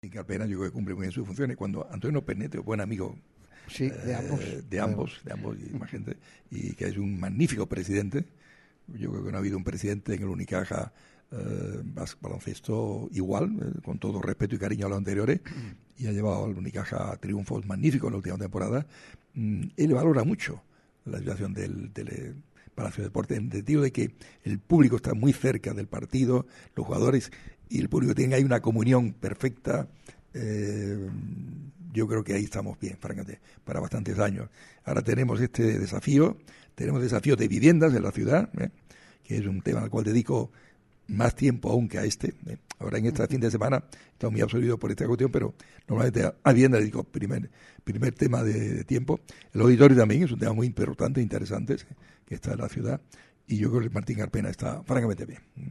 Sobre esta situación también habló el alcalde de Málaga, Francisco de la Torre, en una entrevista exclusiva en Radio MARCA Málaga. Echa freno a las posibles remodelaciones del Martín Carpena.
Ha llevado al Unicaja a muchos éxitos en las últimas temporadas«, aseguraba Francisco de la Torre en la entrevista concedida a Radio MARCA Málaga en la tarde de este lunes 7 de julio.